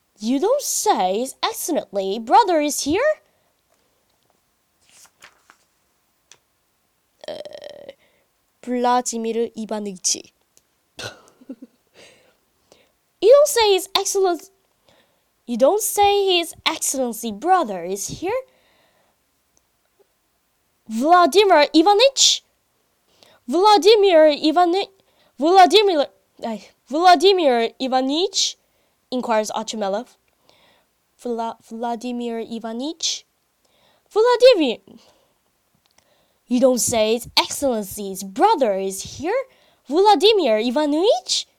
russian_name_vladimir_ivanitch.mp3